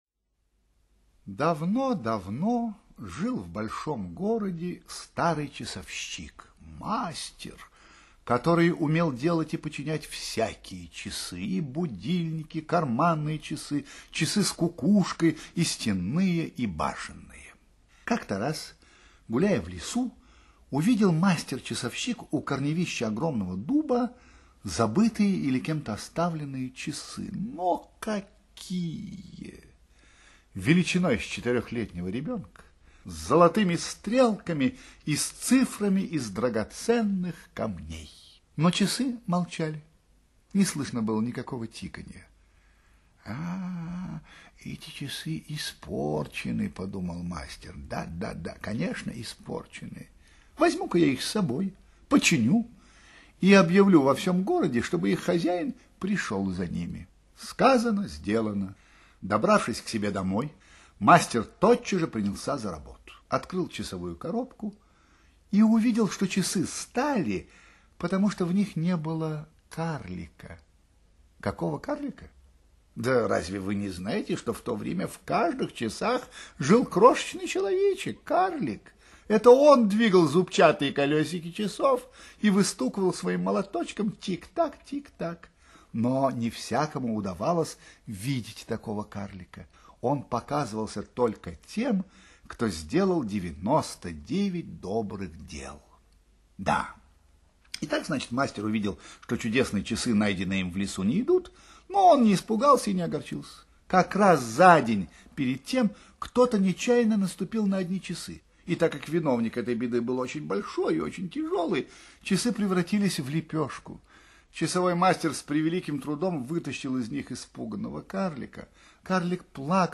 Аудиосказки малышам голосом воспитателя